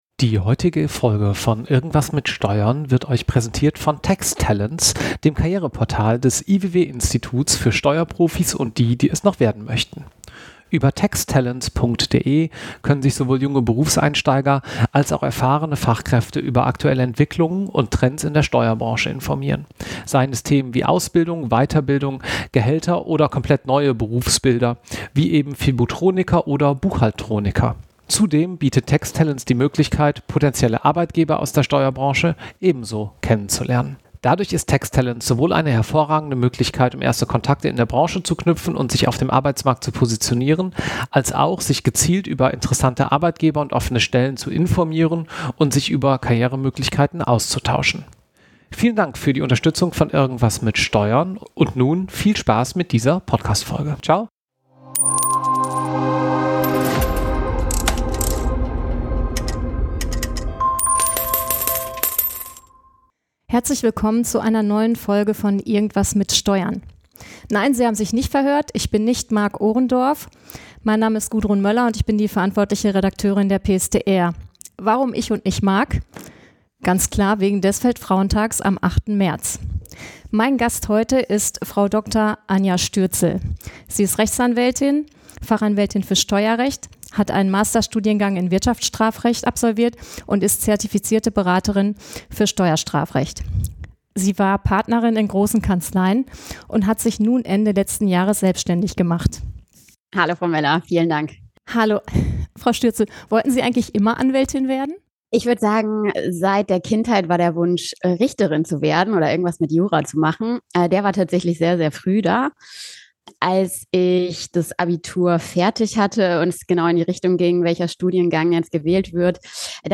Vielfältige Steuer-Berufsbilder im Interview